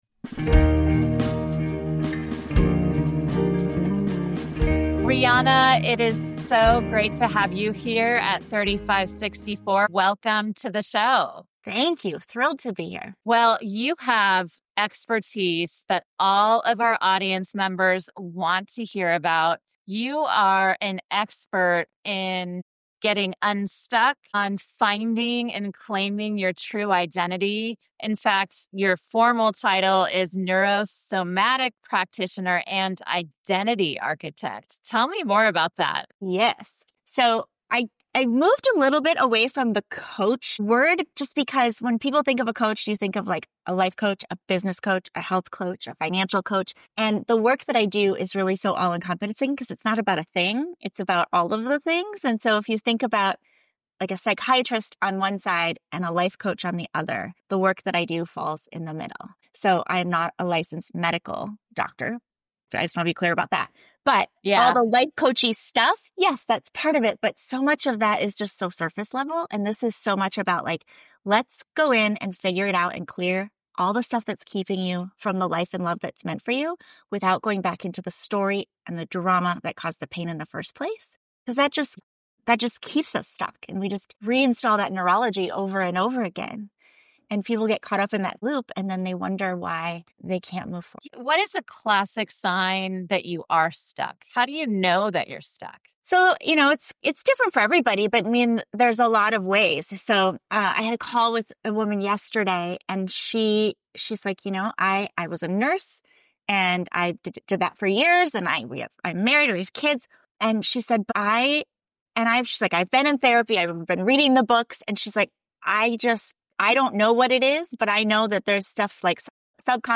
The discussion also touches on midlife reflections, the impact of past trauma, and practical advice for navigating life changes.